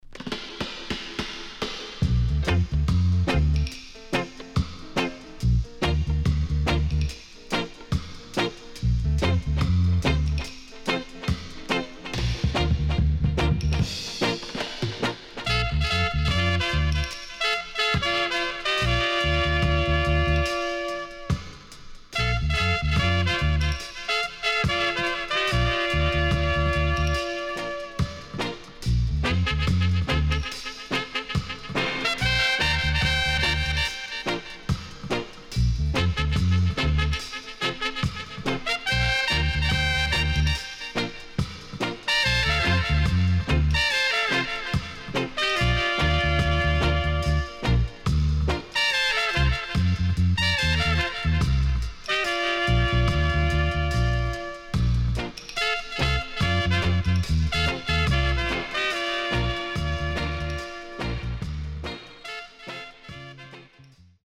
HOME > REGGAE / ROOTS  >  KILLER & DEEP
Killer Roots Vocal & Nice Horn Inst
SIDE A:少しチリノイズ入りますが良好です。